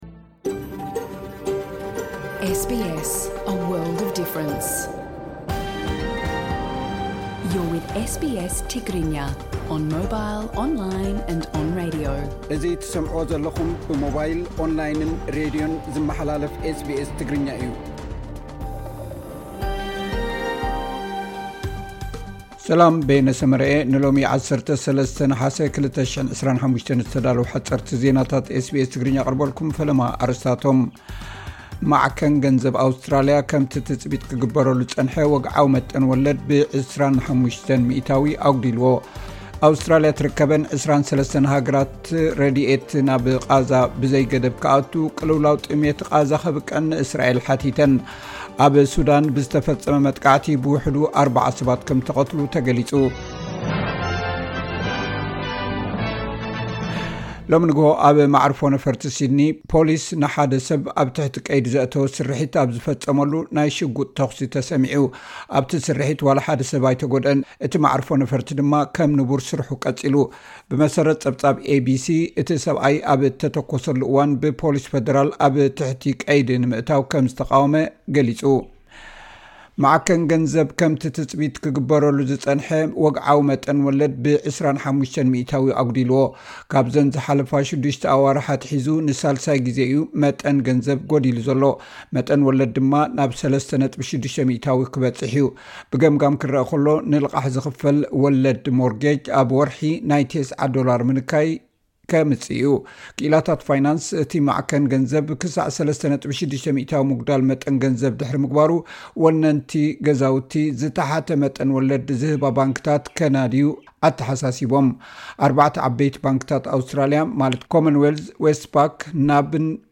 ሓጸርቲ ዜናታት ኤስ ቢ ኤስ ትግርኛ (13 ነሓሰ 2025)